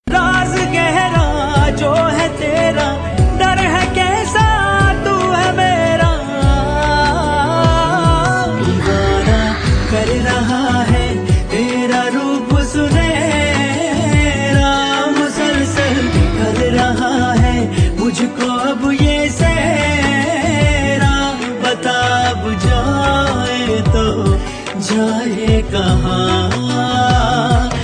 File Type : Latest bollywood ringtone